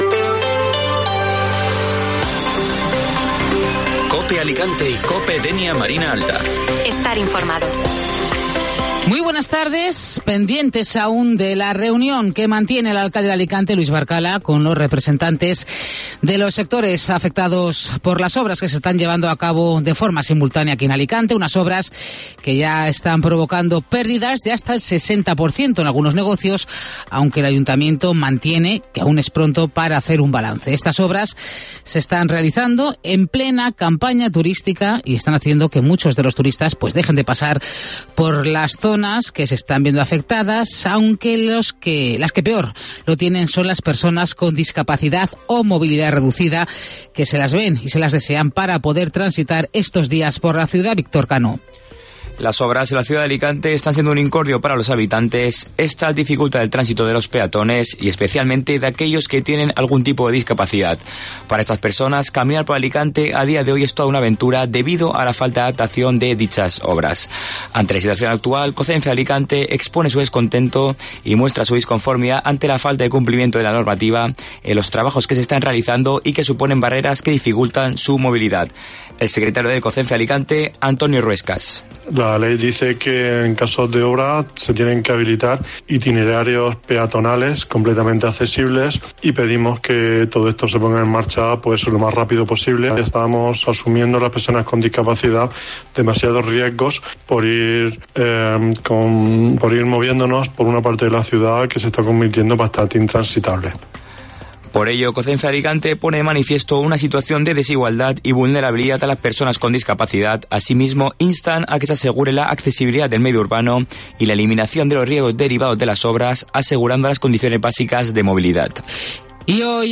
Informativo Mediodía Cope Alicante ( Viernes 14 de julio)